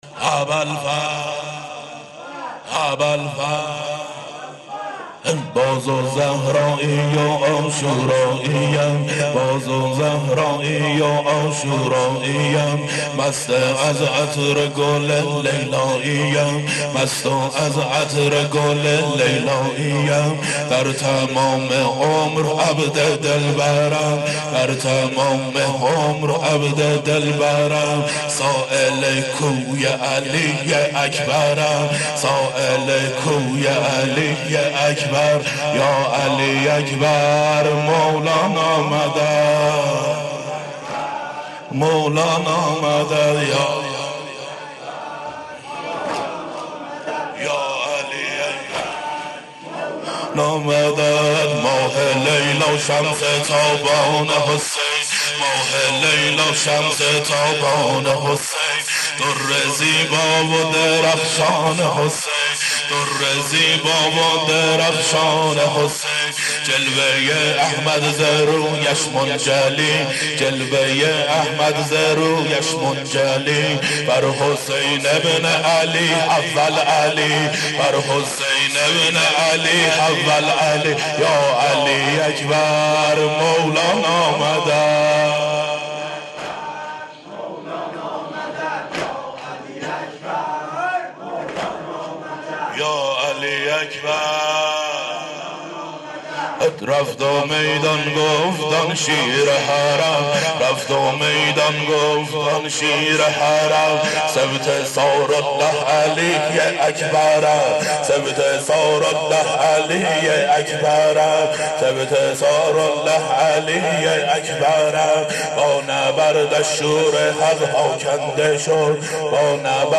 sineh-zani-shab-8-moharam-93.mp3